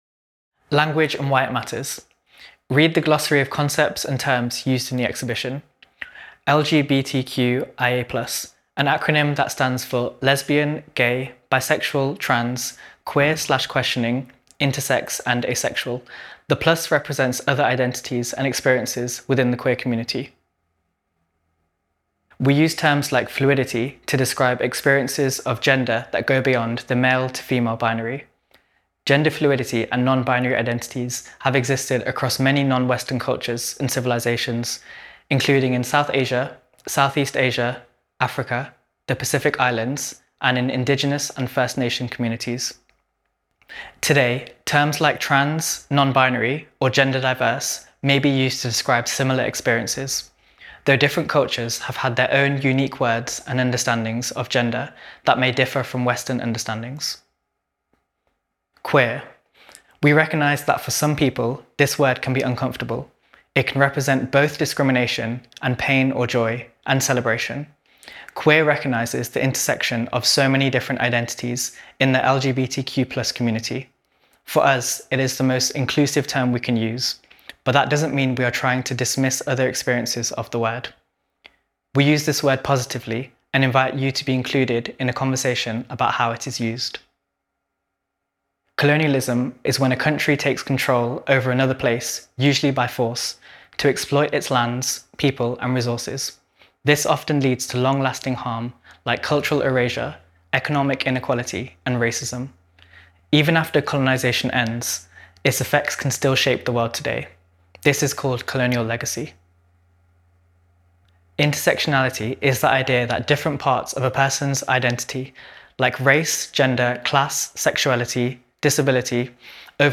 We Have Always Been Here – Audio Descriptions of Exhibition Artwork
Panels – audio